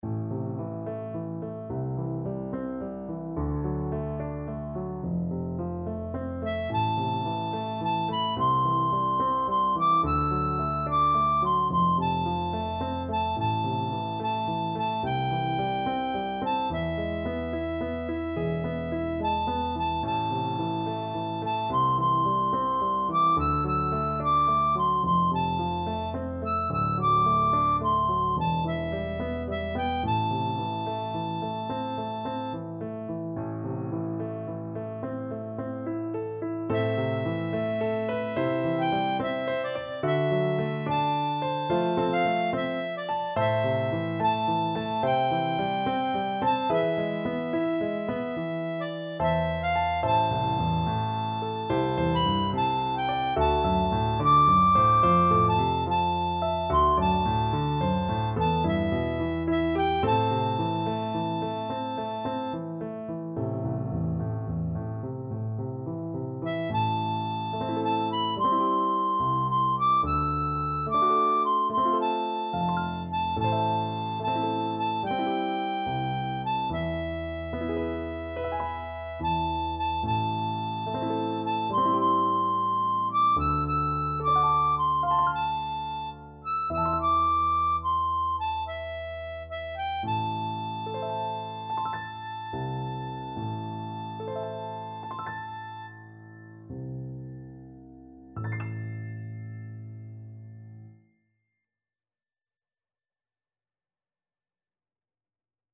House_of_the_rising_sun_HARM.mp3